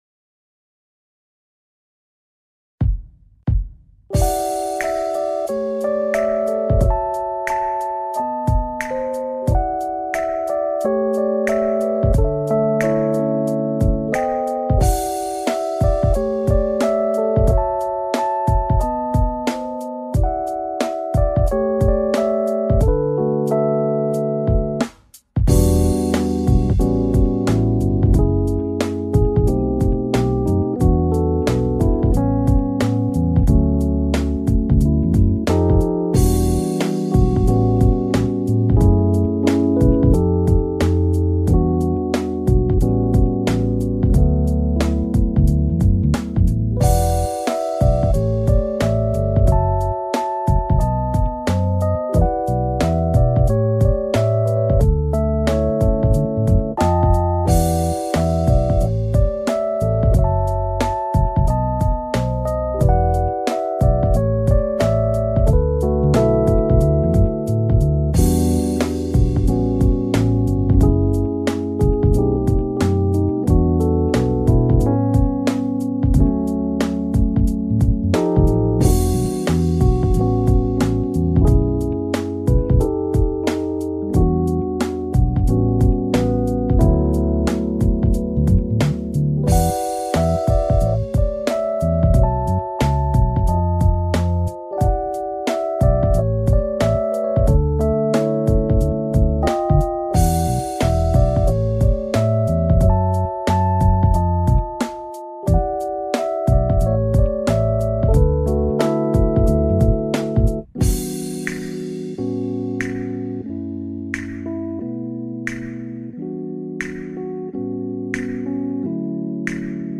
clean instrumental